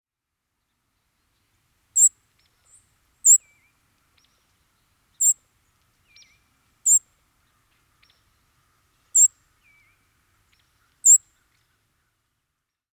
Fox Sparrow
How they sound: Their calls include smack , chu-chu , and sip noises.